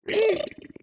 Minecraft Version Minecraft Version 1.21.5 Latest Release | Latest Snapshot 1.21.5 / assets / minecraft / sounds / mob / strider / happy1.ogg Compare With Compare With Latest Release | Latest Snapshot